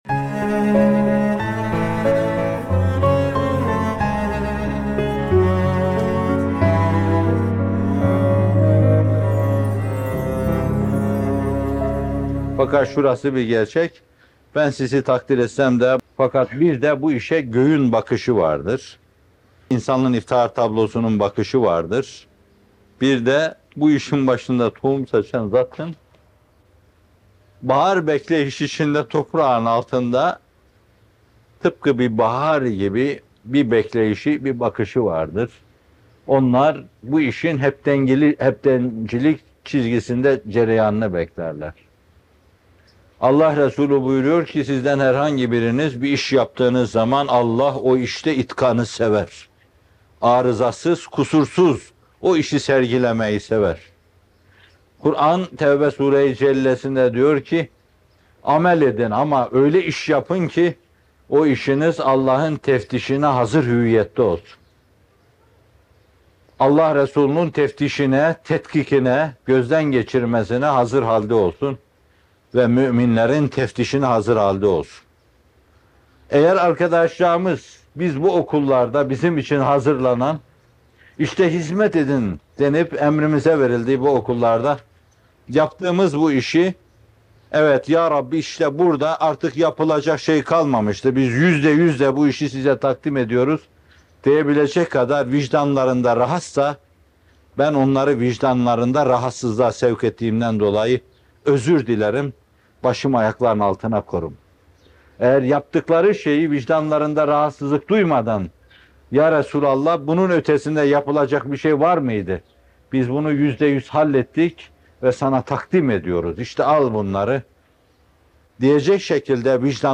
İtkan Ufkunda Hizmet, Fikir Sancısı ve Ümitlerimiz - Fethullah Gülen Hocaefendi'nin Sohbetleri